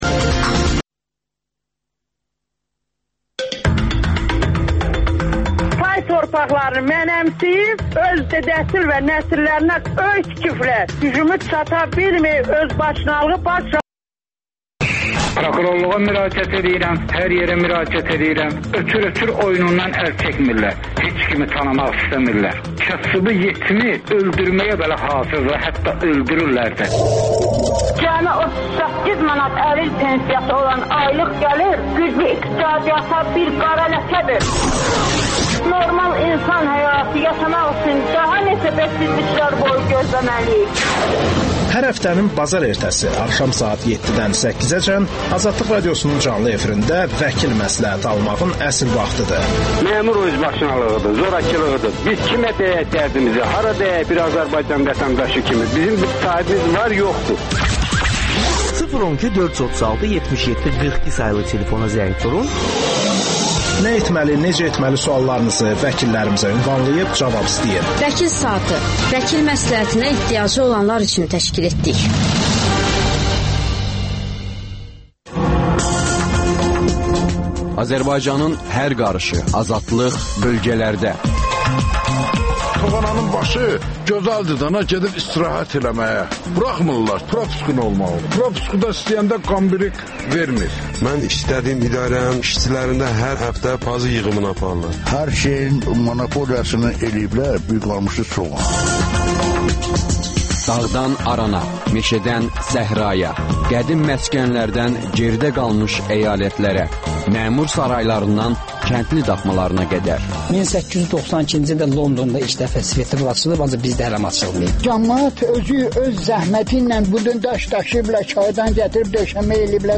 AzadlıqRadiosunun müxbirləri ölkə və dünyadakı bu və başqa olaylardan canlı efirdə söz açırlar. Günün sualı: Jurnalistin döyülməsi cəmiyyət üçün nə deməkdir?